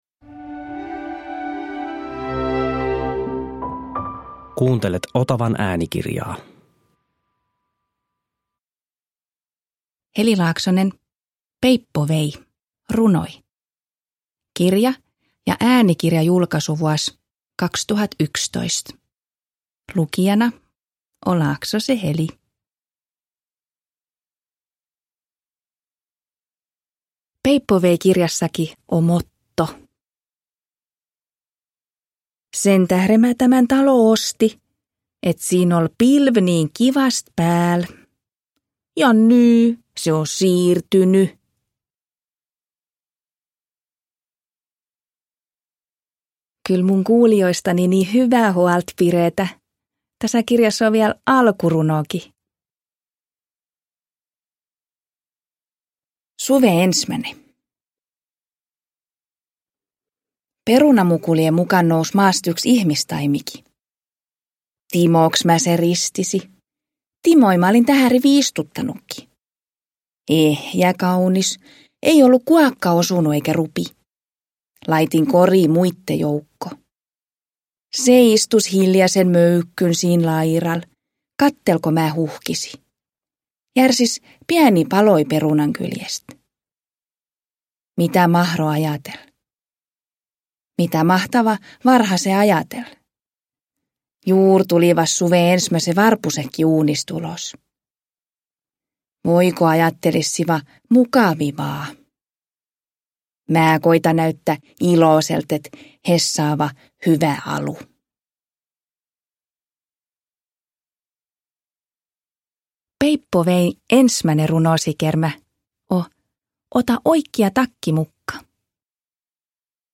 Peippo vei – Ljudbok – Laddas ner
Äänikirjan lukee kirjailija itse.
Uppläsare: Heli Laaksonen